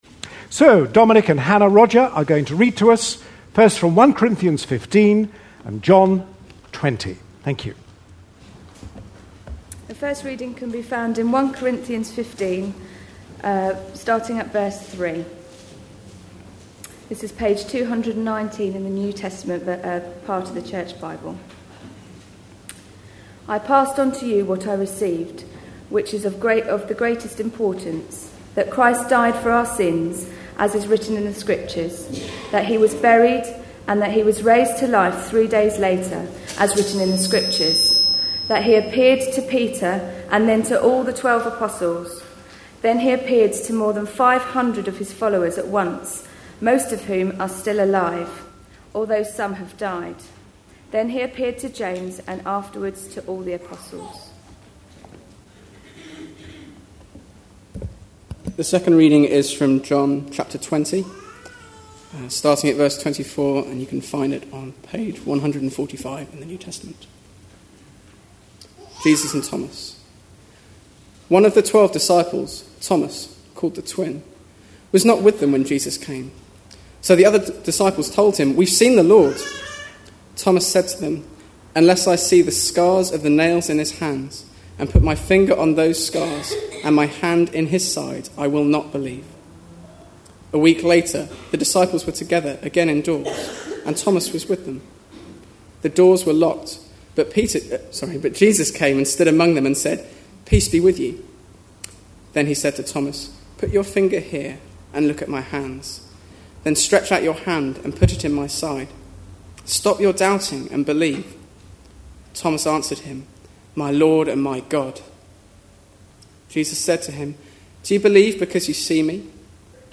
A sermon preached on 24th April, 2011, as part of our A Passion For.... series.